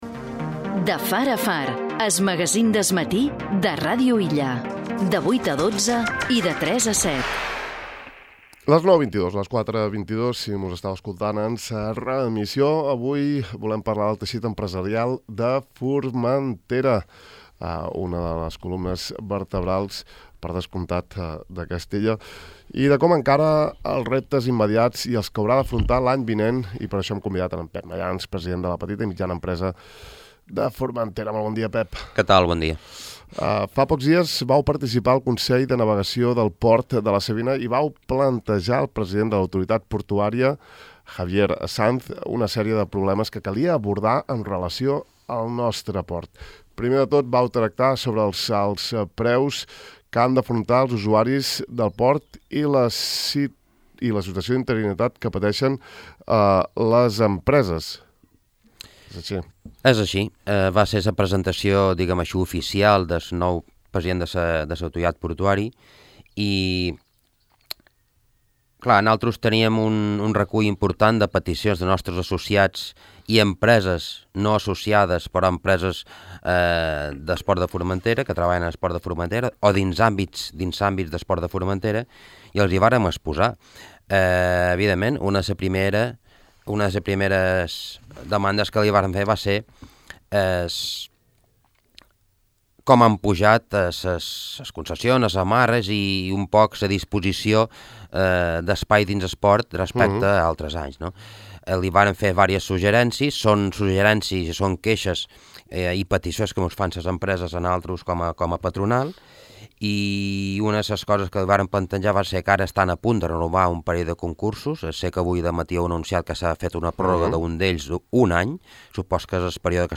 Sobre això l’hem interpel·lat aquest matí al nostre programa De far a far, on ens ha concedit una entrevista.